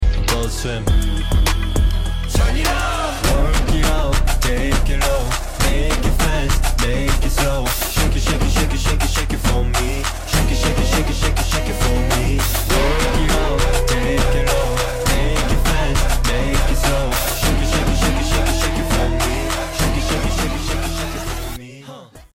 audios with beats